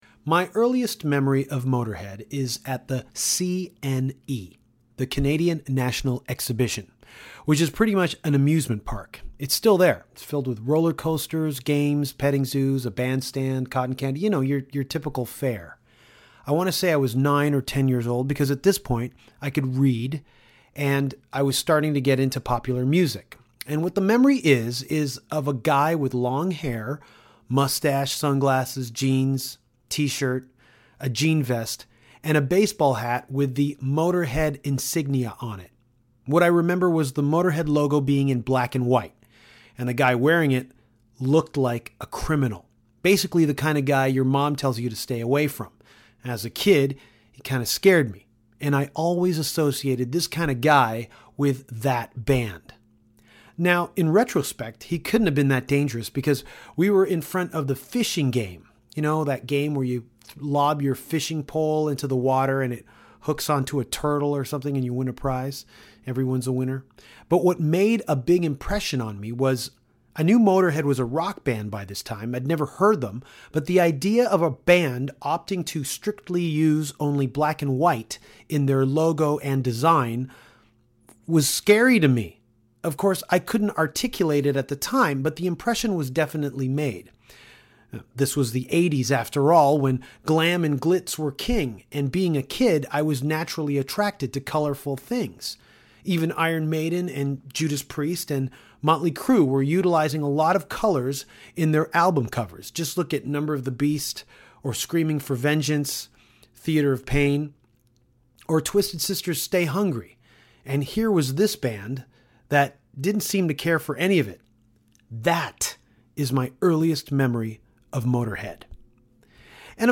While on the Motörhead Motörboat cruise, Danko caught up with Motörhead guitarist, Phil Campbell, to talk about Phil Campbell’s All Starr Band, Fireball Ministry, pranking Testament & Duff McKagan and The Campbell Comedy Corporation.